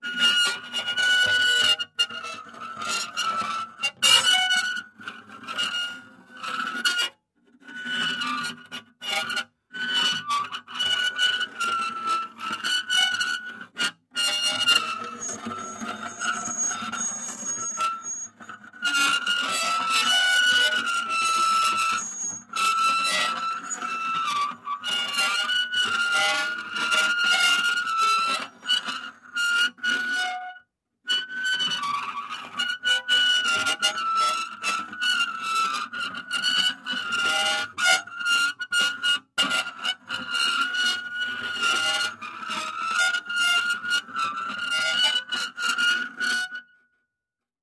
Звуки мизофонии
Скрип металлического стула по кафельному полу